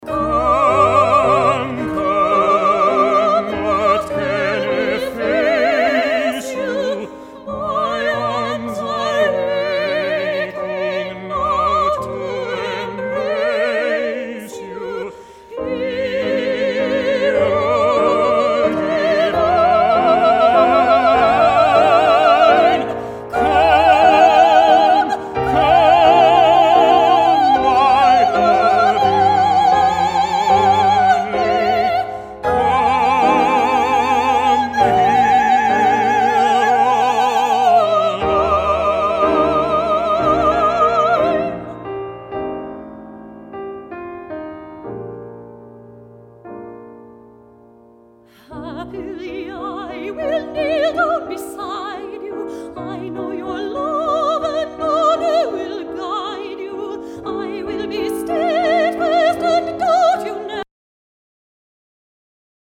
soprano
baritone